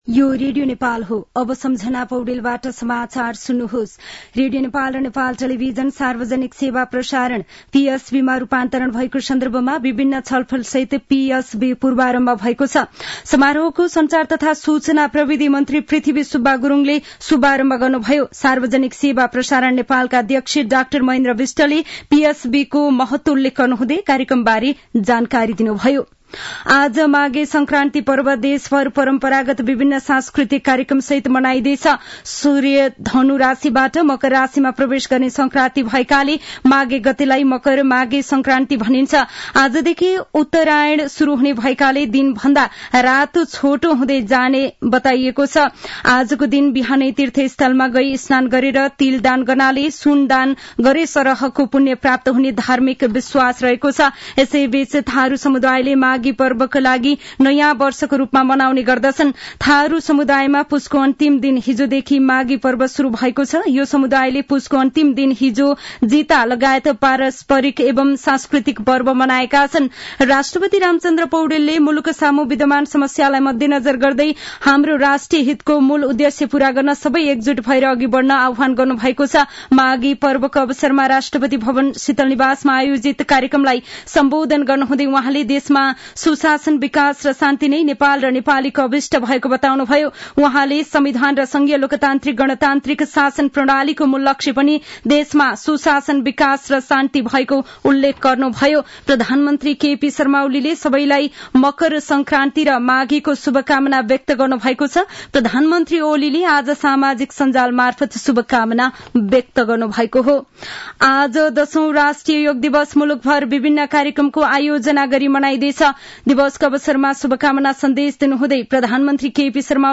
मध्यान्ह १२ बजेको नेपाली समाचार : २ माघ , २०८१